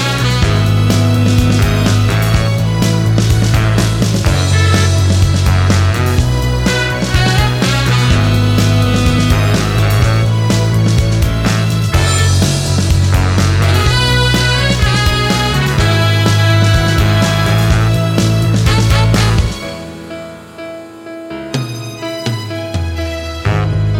Down 2 Semitones Pop (2000s) 3:05 Buy £1.50